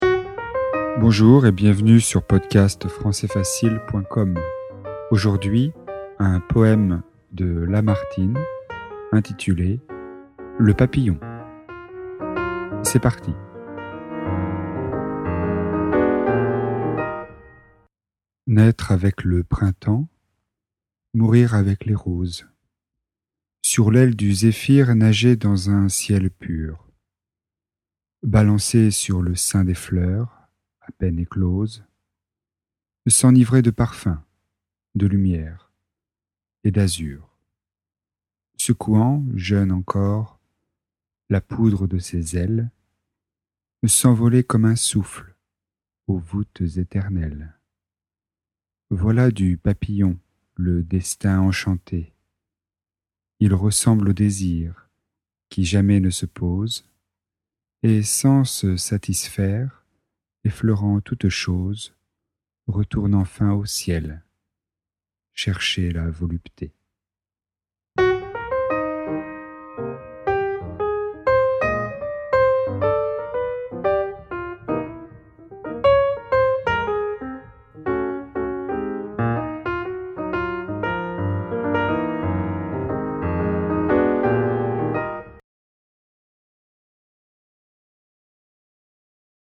Poésie, niveau intermédiaire (B1).